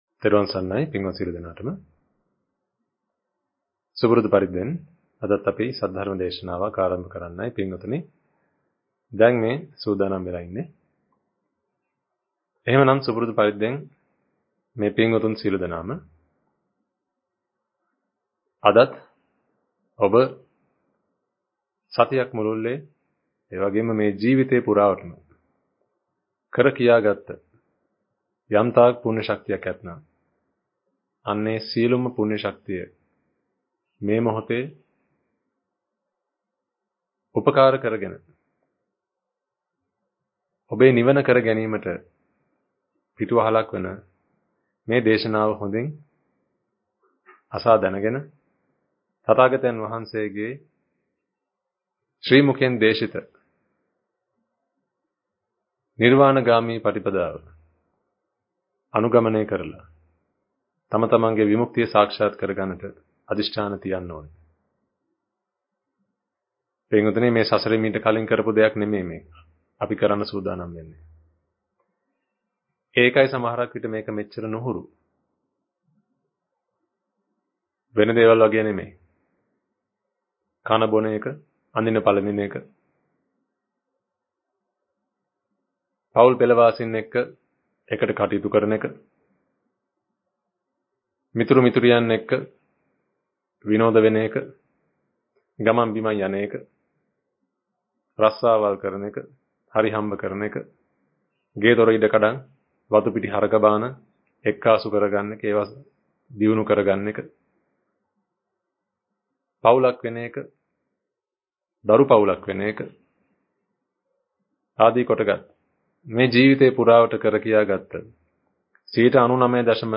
Sermon | JETHAVANARAMA